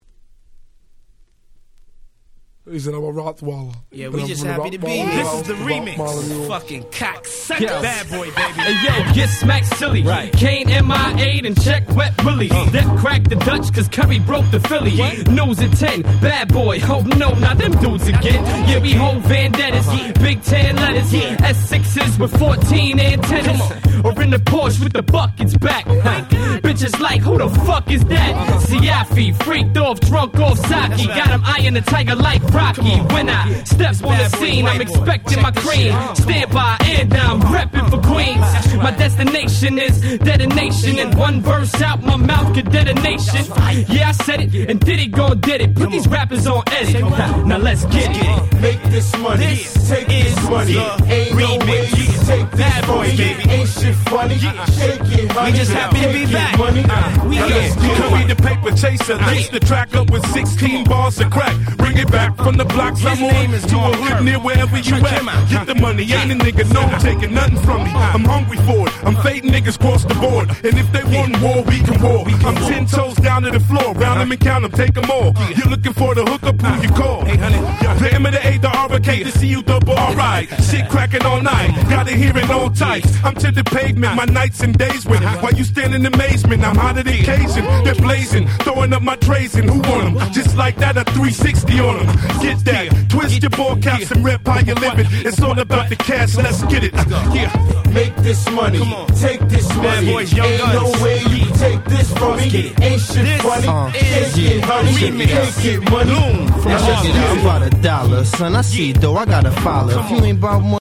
超絶Club Hit 00's Hip Hop !!!!!